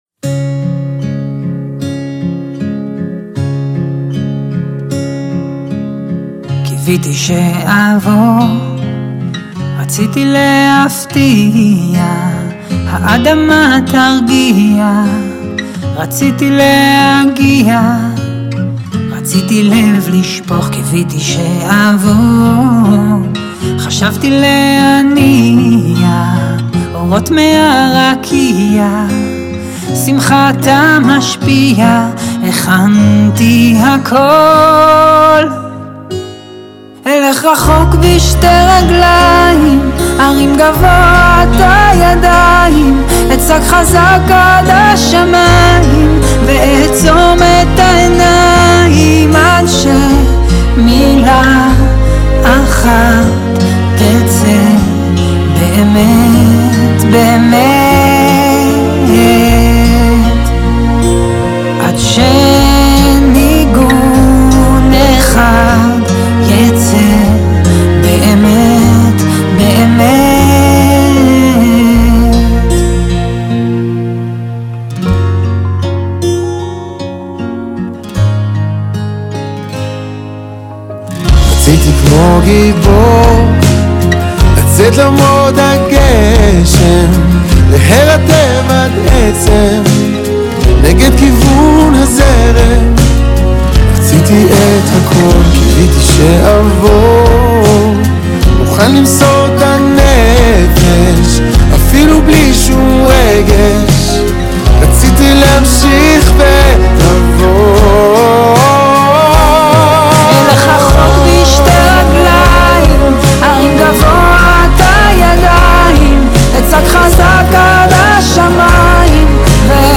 בדואט